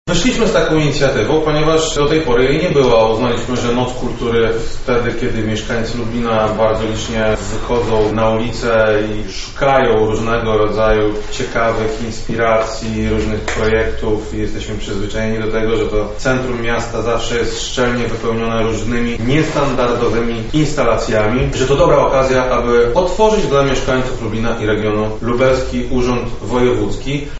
O pomyśle mówi Krzysztof Komorski, Wojewoda Lubelski.